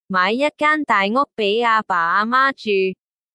Google Translate 自 2024 年 7 月起支援廣東話，令廣東話母語使用者感到自己嘅語言俾人多咗一啲關注，但你會發現喺有比較嘅情況下，佢嘅廣東話點解咁生硬呢？
translate_tts.mp3